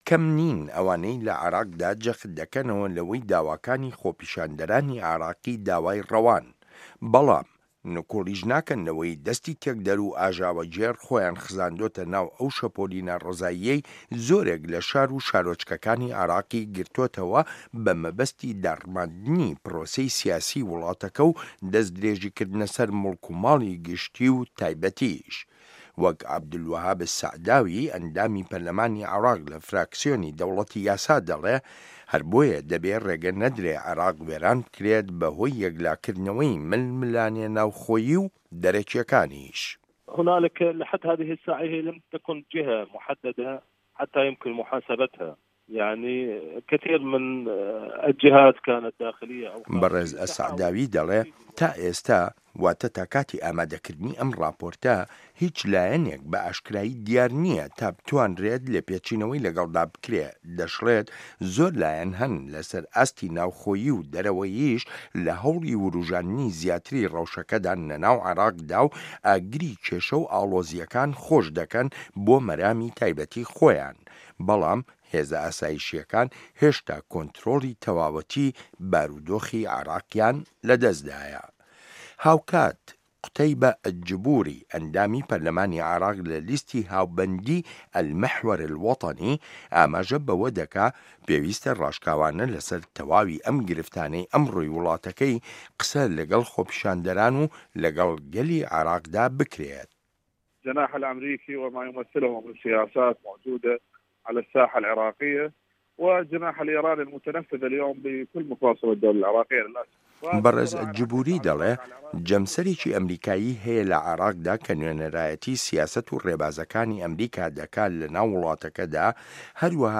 ڕاپۆرتێکی ئامادەکردووە.